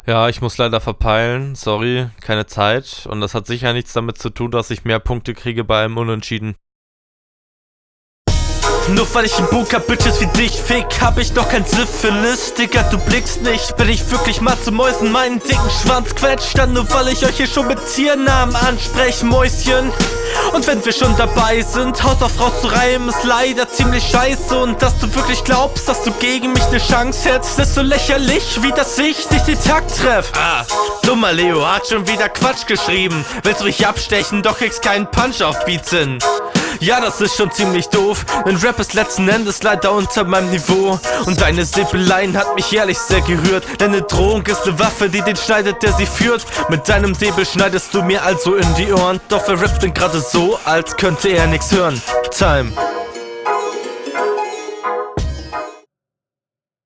Sehr sauber geflowed meiner Meinung nach, gefällt mir richtig gut.